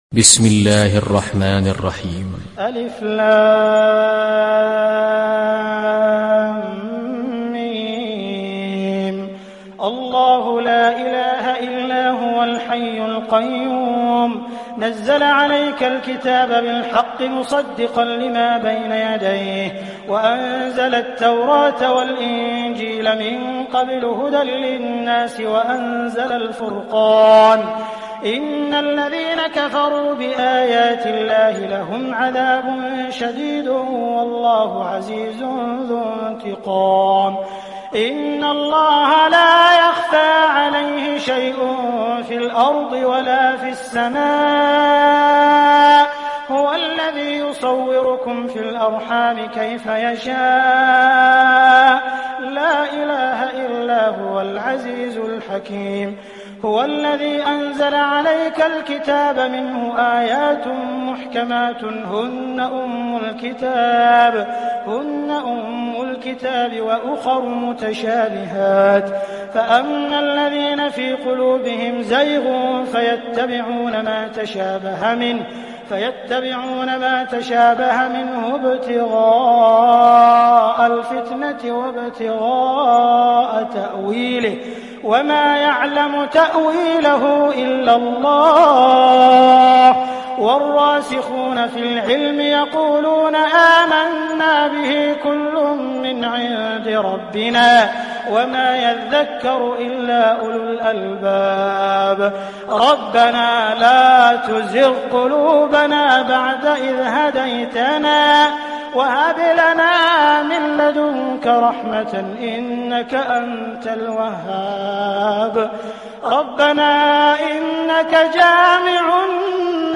دانلود سوره آل عمران mp3 عبد الرحمن السديس (روایت حفص)